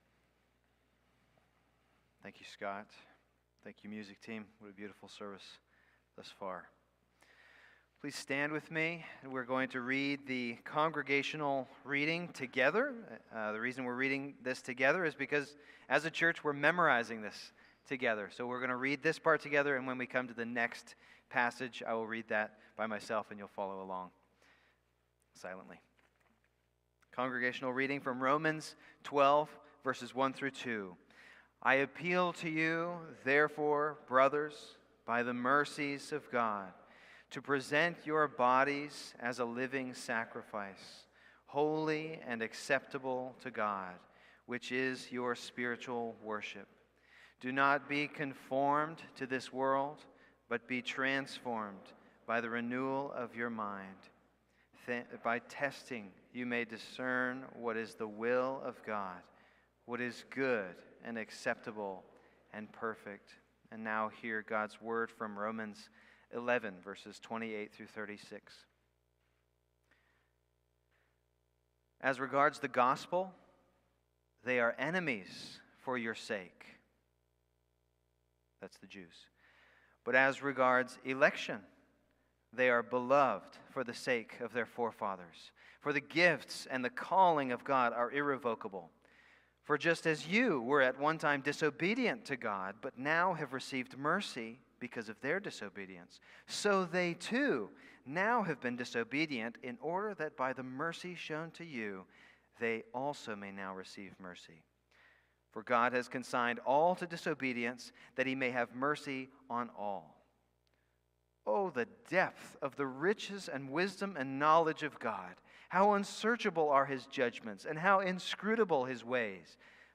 February-9-Worship-Service.mp3